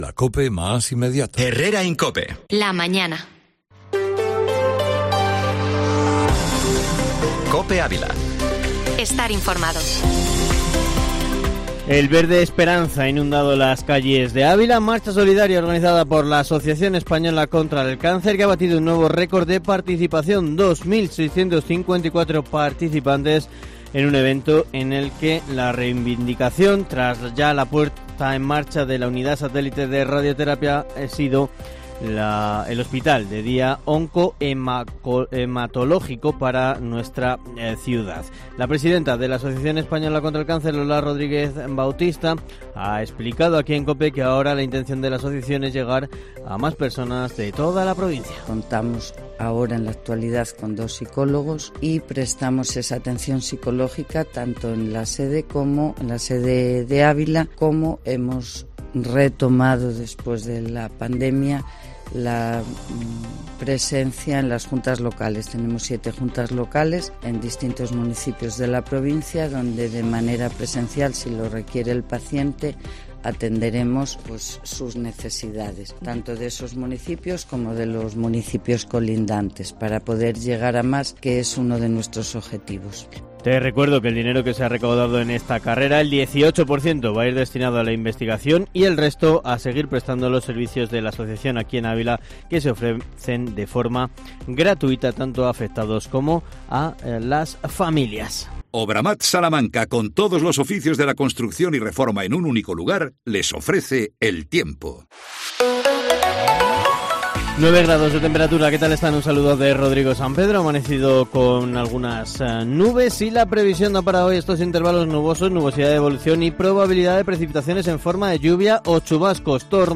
Informativo Matinal Herrera en COPE Ávila